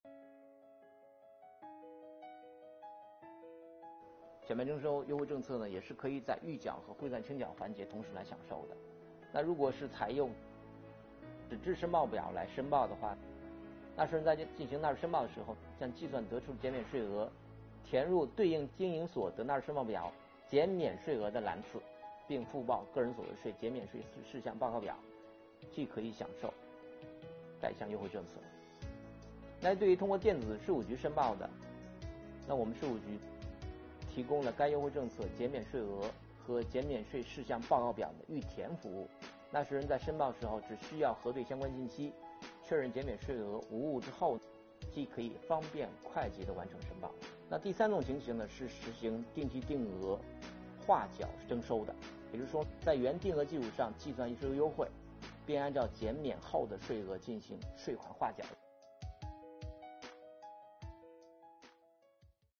近日，国家税务总局推出最新一期“税务讲堂”课程，国家税务总局所得税司副司长王海勇介绍并解读了小型微利企业和个体工商户所得税优惠政策。